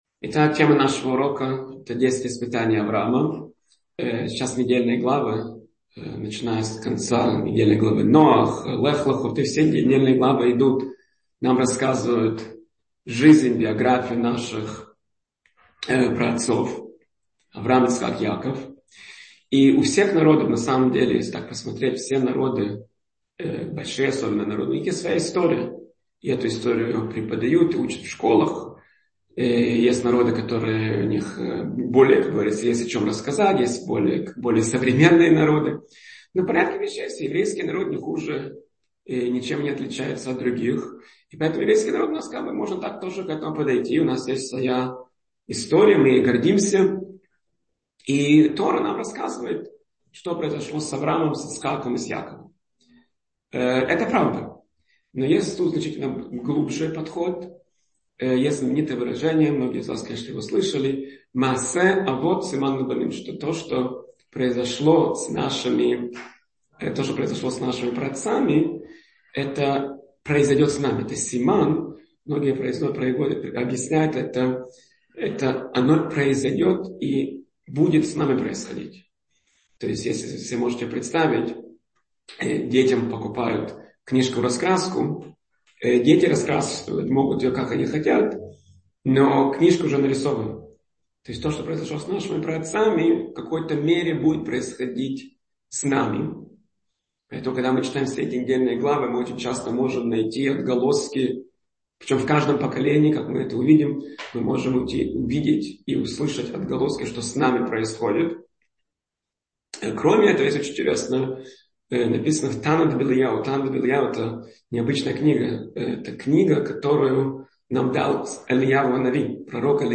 Аудиоуроки